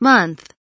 month kelimesinin anlamı, resimli anlatımı ve sesli okunuşu